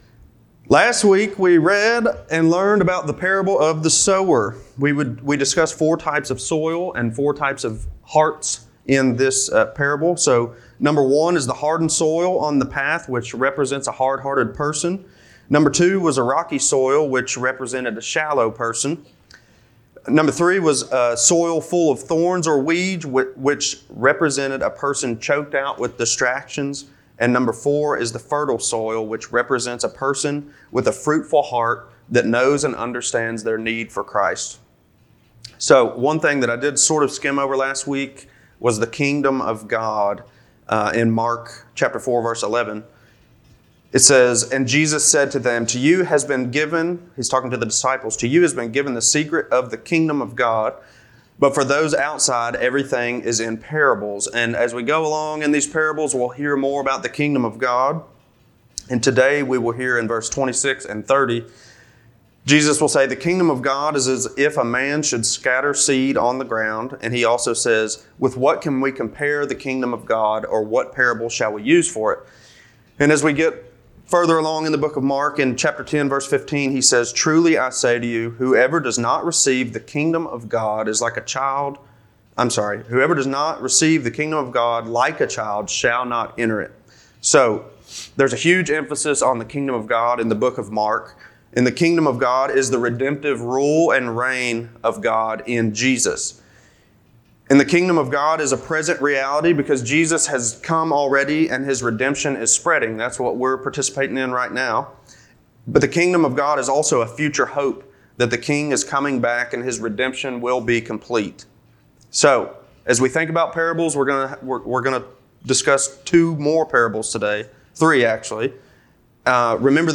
Sunday Morning Services | Belleview Baptist Church